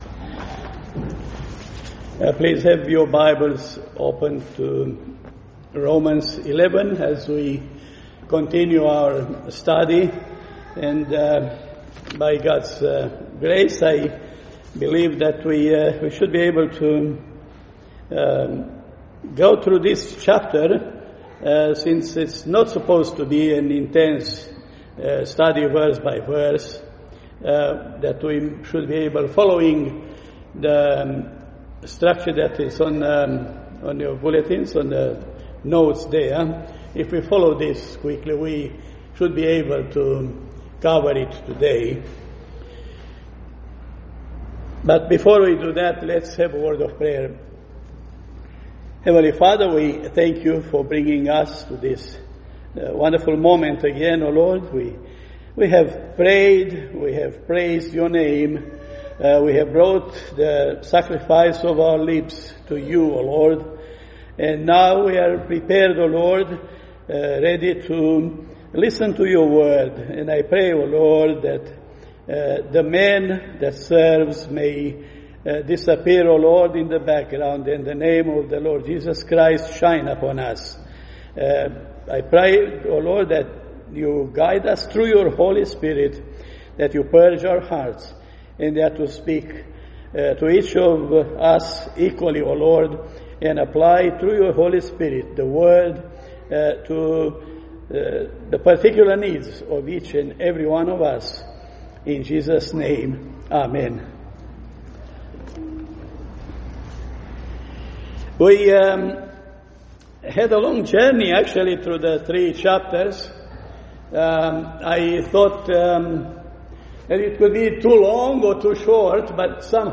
Passage: Romans 11:1-36 Service Type: Sunday Morning